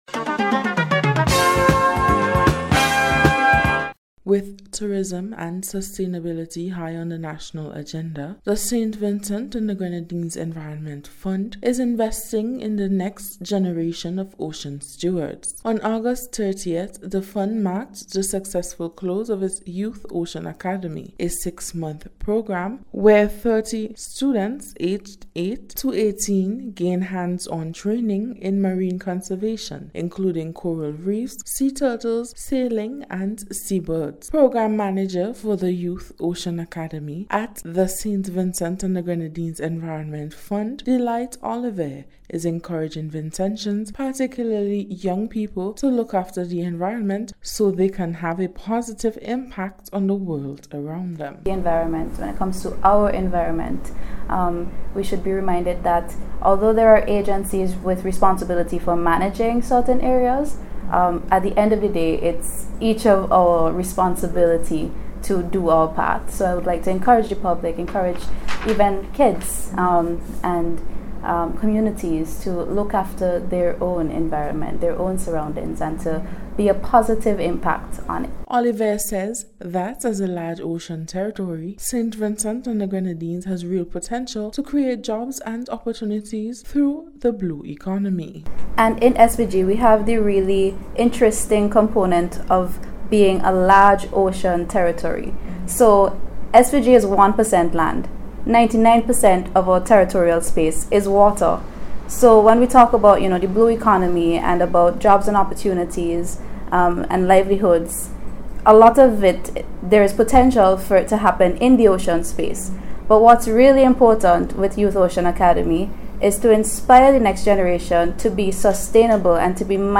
NBC’s Special Report- Monday 15th September,2025